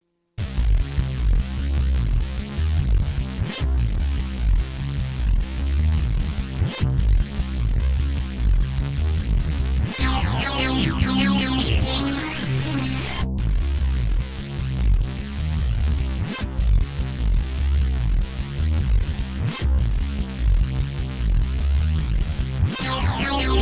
Deep Impact Gore Trance